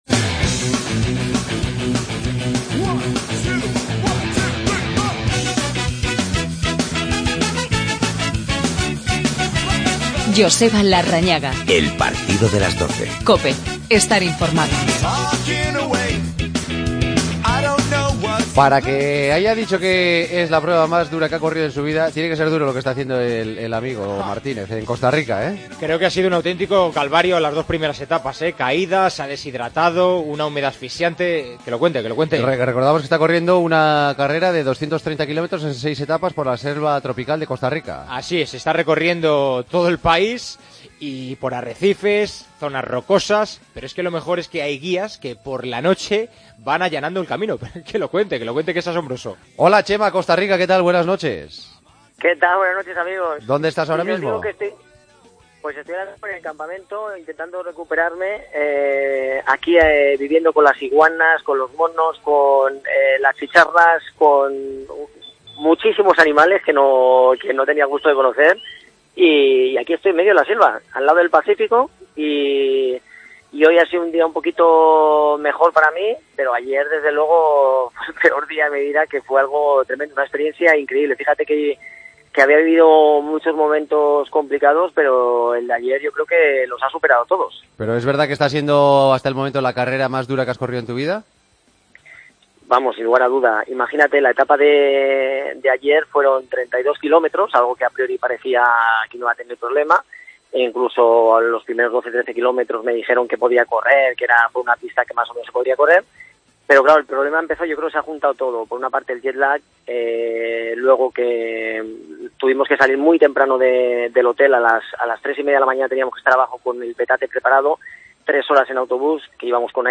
AUDIO: Conexión con Costa Rica, donde Chema Martínez está disputando una carrera de más de 200 kilómetros en varias etapas...